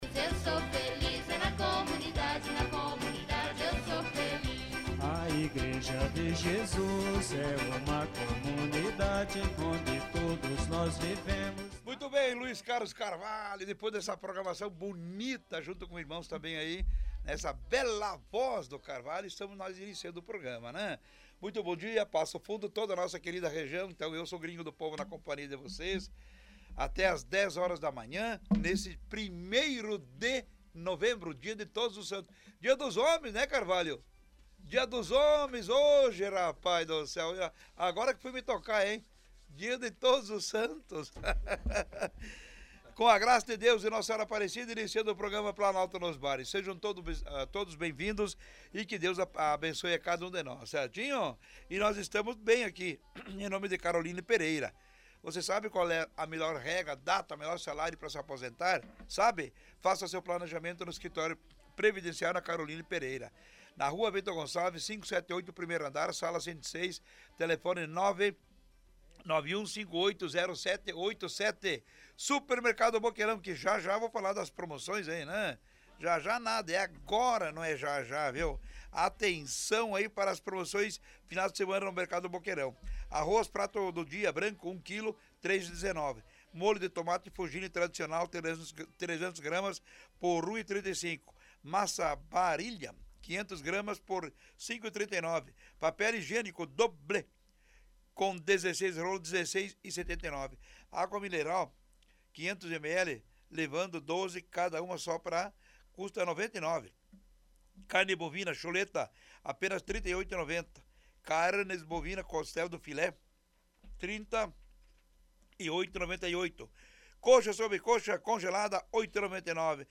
Neste sábado, 01, participaram os representantes do Bairro Hípica, em Passo Fundo.